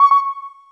chat-inbound.wav